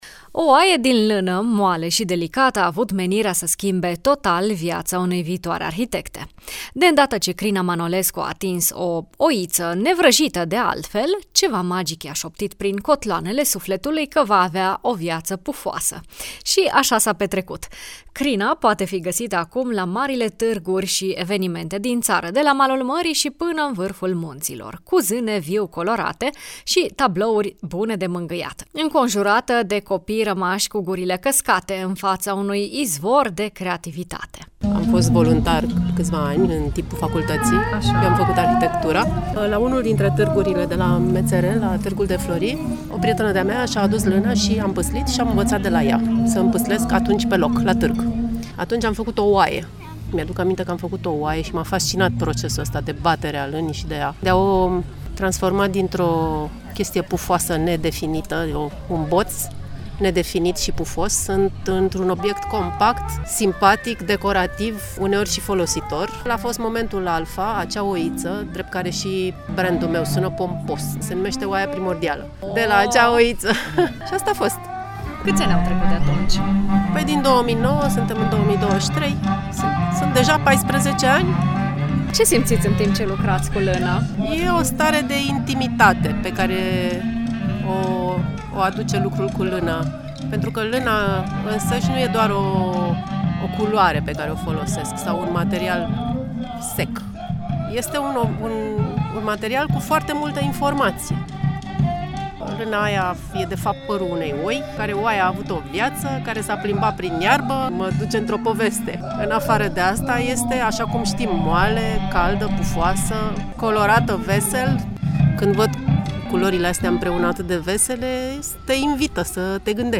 artizan de lână împâslită: